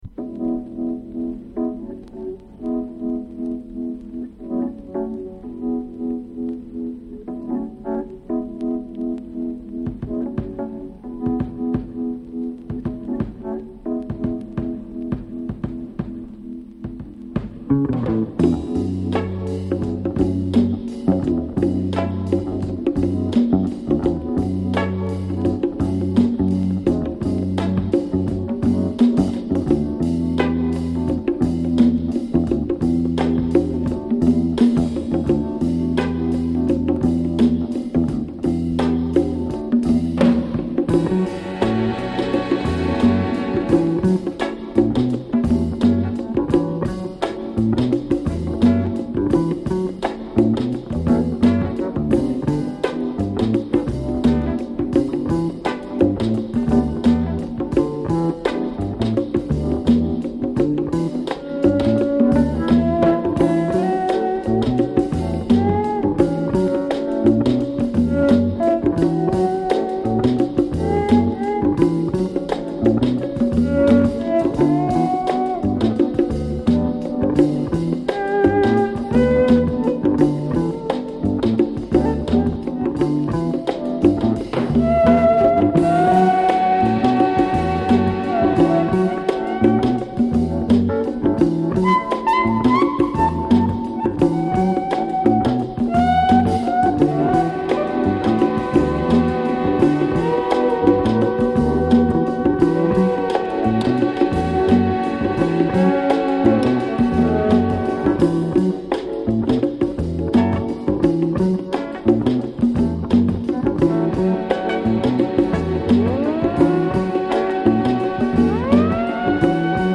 2.  > O.S.T